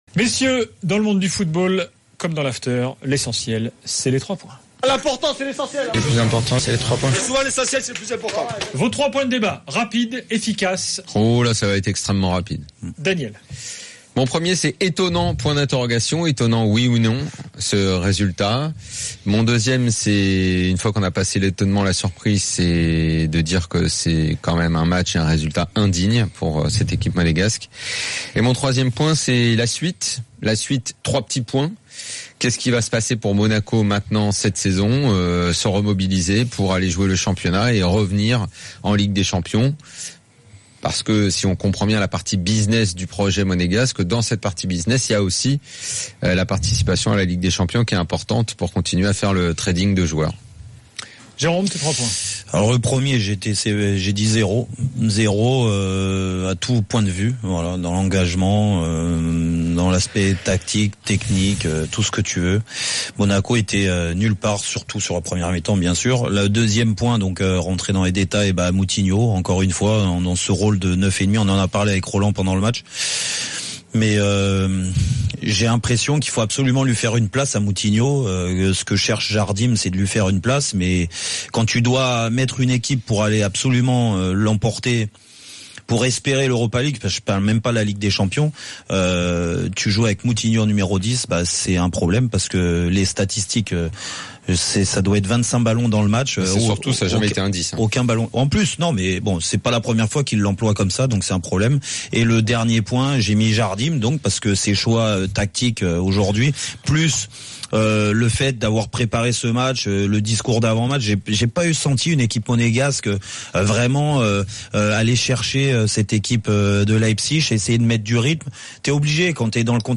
Chaque jour, écoutez le Best-of de l'Afterfoot, sur RMC la radio du Sport.